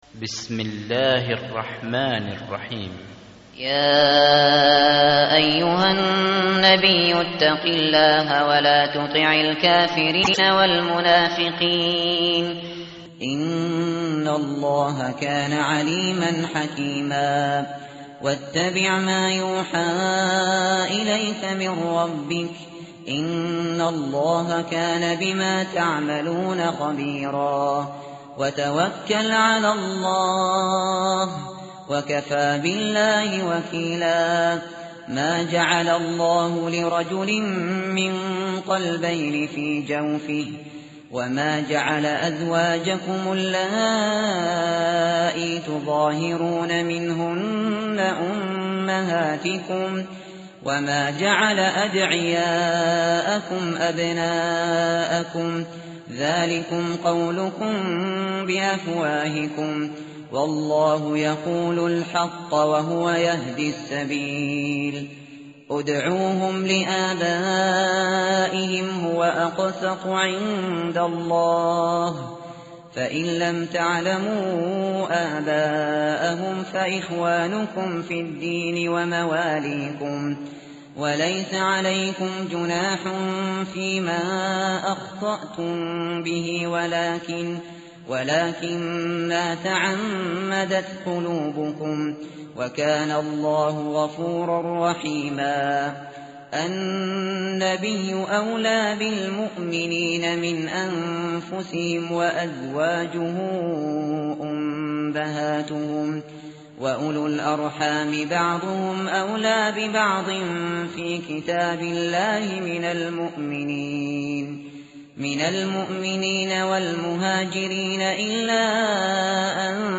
tartil_shateri_page_418.mp3